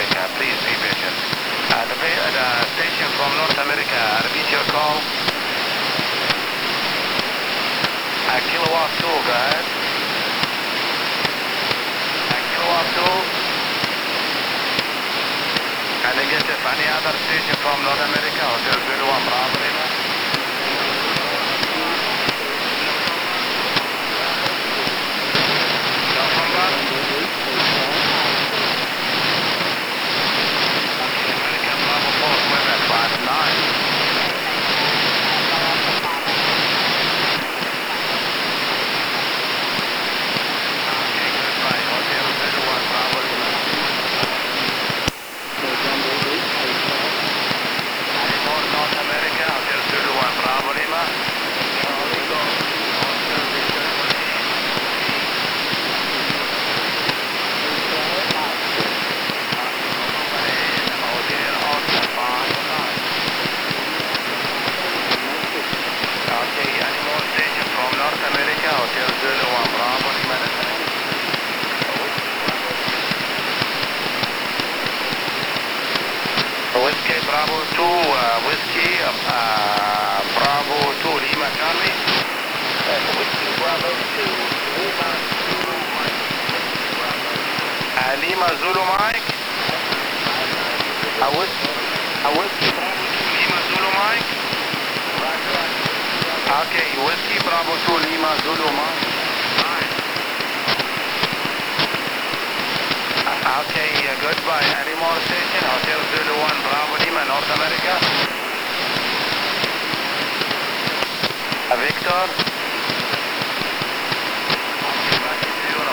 Monitoring the dual receiver output with headphones is interesting. Background white noise appears as a wide stereo field while wanted signals appear in the centre of the stereo image. It was easier to read a weak signal low down in the noise especially during fading as often fading is due to polarisation changes over ionospheric paths.
Dual_receiver_test1.wav